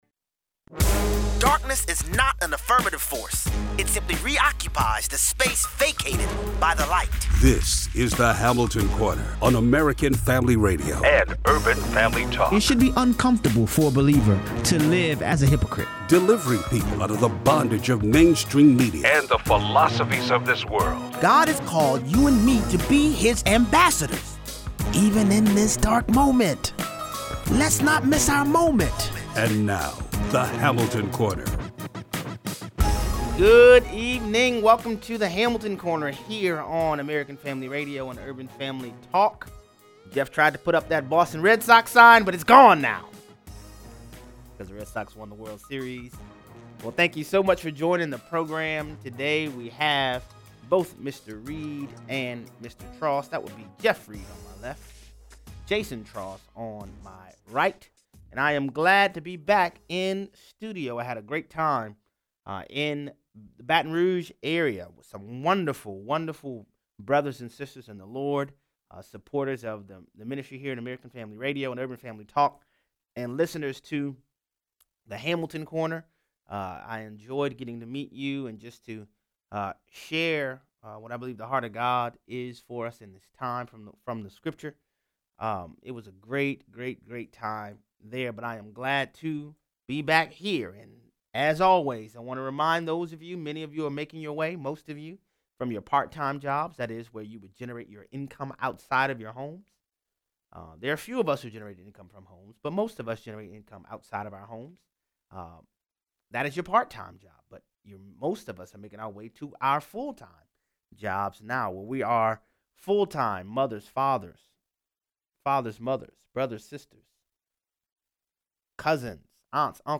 The European Court of Human Rights issues a ruling that shreds freedom of expression. 0:43 - 0:60: Hillary Clinton says she’s “like to be president...” and you thought she was done. Callers weigh in.